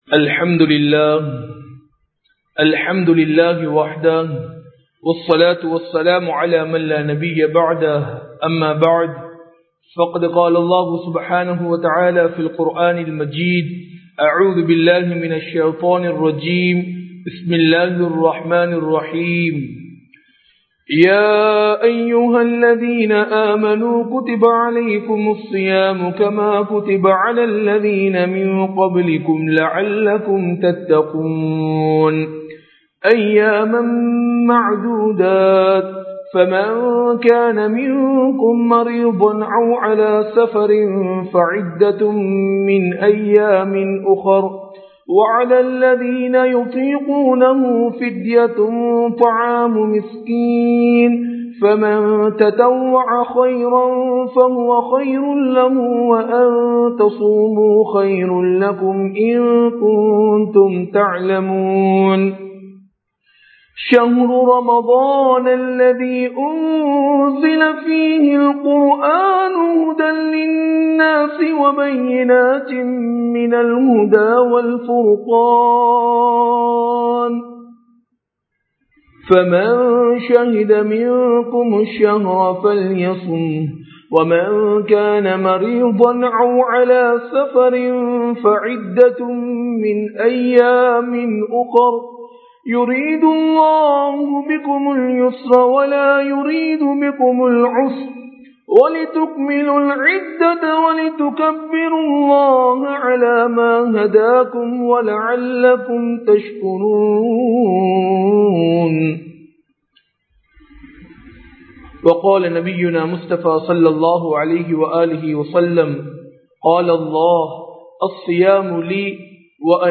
ரமழானும் 07 இபாதத்களும் | Audio Bayans | All Ceylon Muslim Youth Community | Addalaichenai
Majma Ul Khairah Jumua Masjith (Nimal Road)